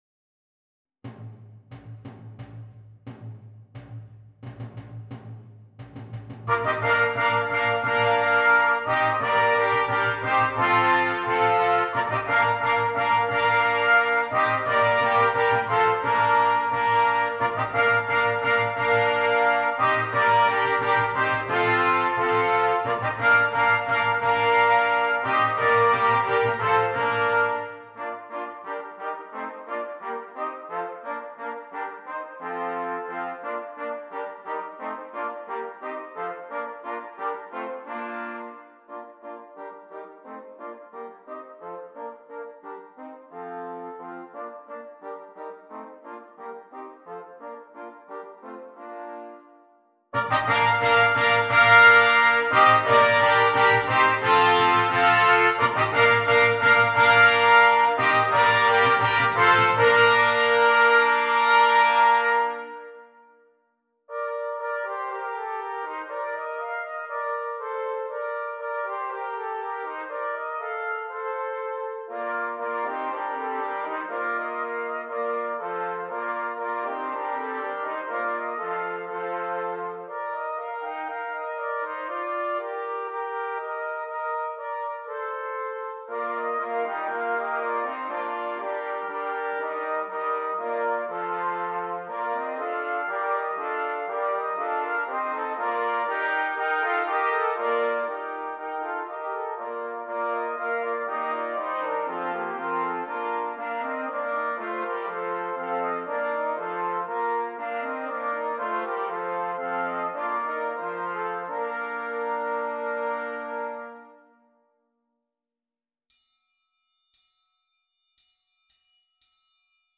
6 Trumpets and optional Percussion
Brilliant fortes are contrasted with smooth lyrical playing.